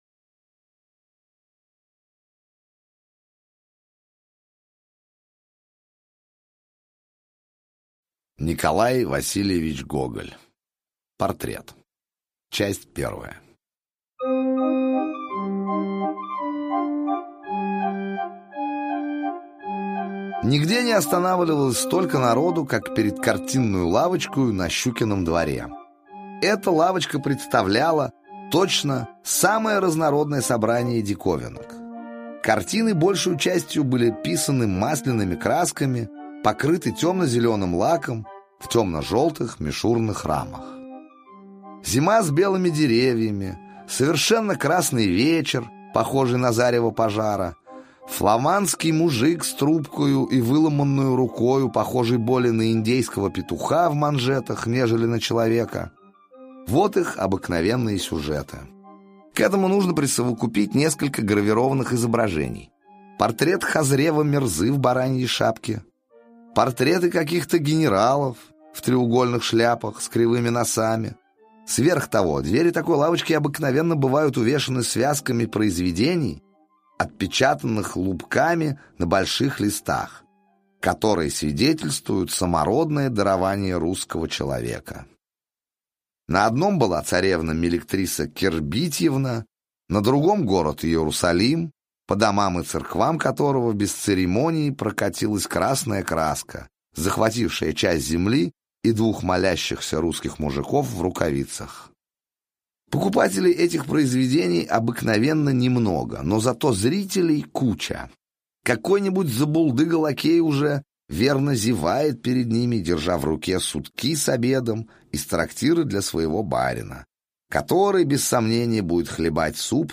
Портрет - аудио повесть Гоголя - слушать повесть онлайн